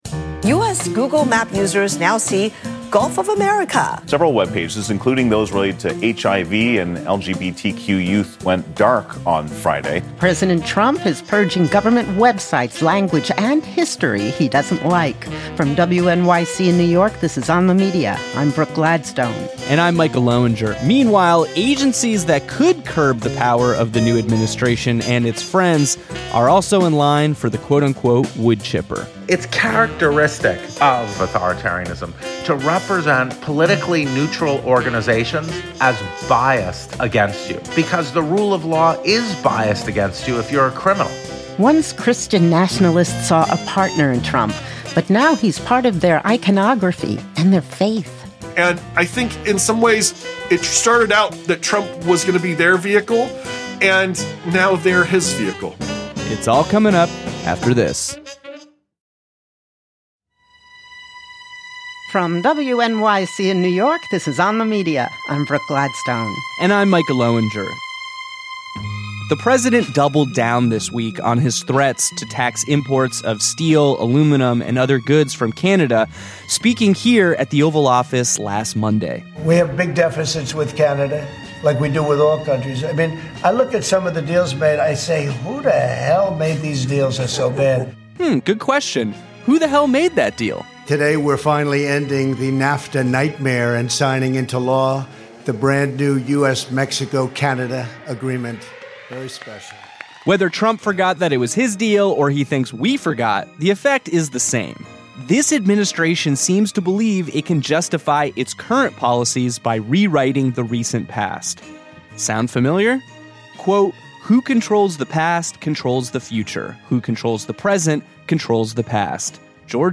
Read an adapted and updated version of the 2025 James M. Banner, Jr., Lecture on the State of the Discipline of History.